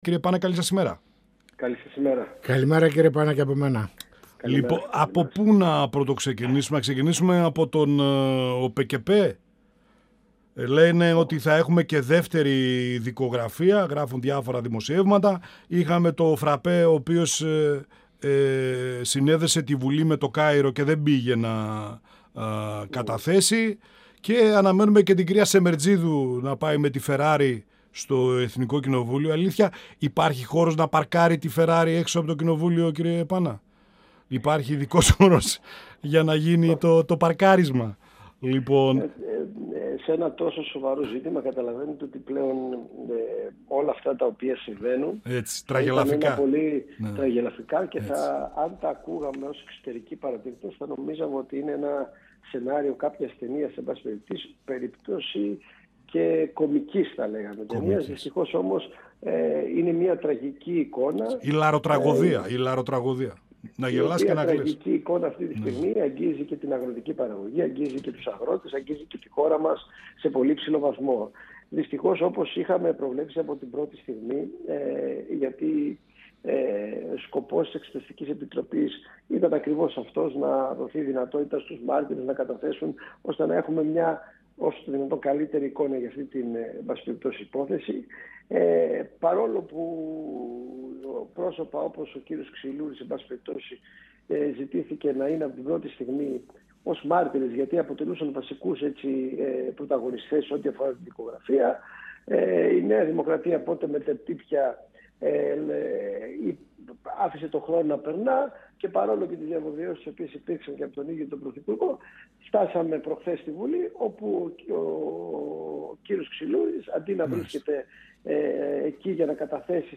Στις επερχόμενες αγροτικές κινητοποιήσεις, το σκάνδαλο του ΟΠΕΚΕΠΕ, καθώς και τη ρευστότητα στο χώρο της Κεντροαριστεράς, με αφορμή το βιβλίο «Ιθάκη» του Αλέξη Τσίπρα αναφέρθηκε ο Βουλευτής του ΠΑΣΟΚ Απόστολος Πάνας, μιλώντας στην εκπομπή «Πανόραμα Επικαιρότητας» του 102FM της ΕΡΤ3.
Συνεντεύξεις